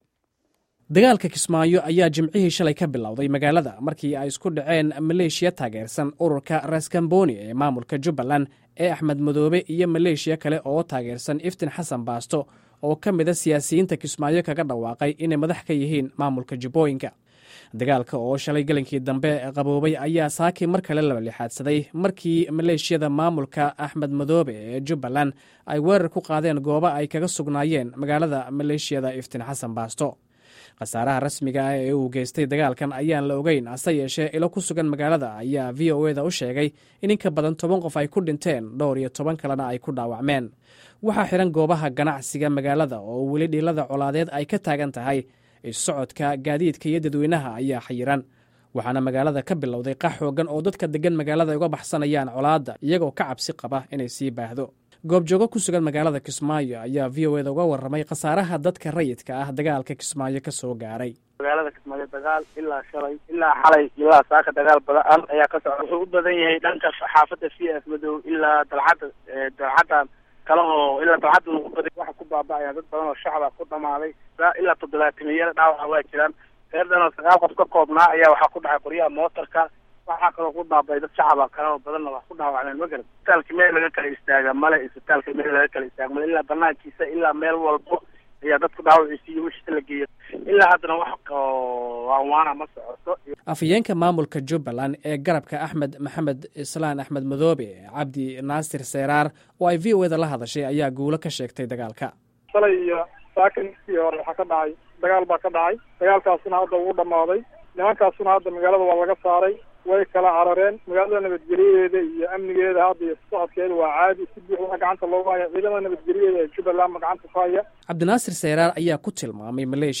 Dhageyso warbixin ka hadleysa wararkii ugu dambeeyay ee dagaalka Kismayo